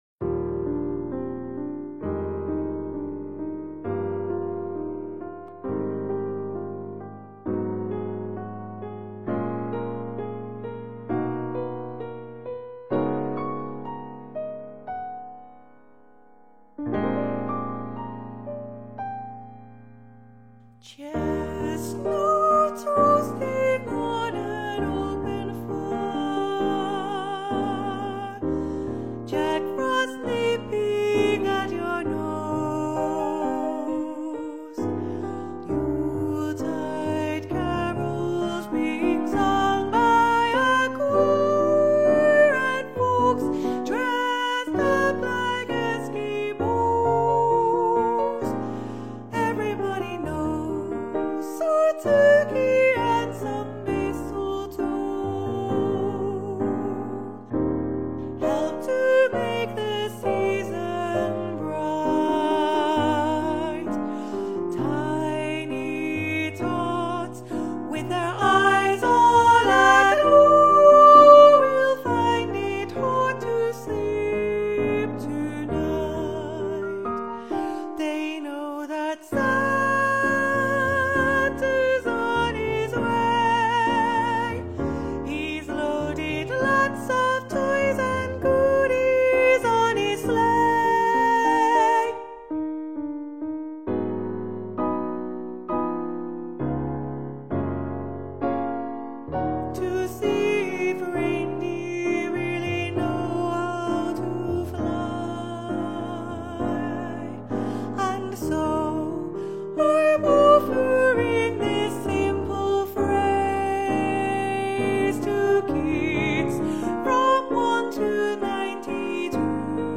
- Soprano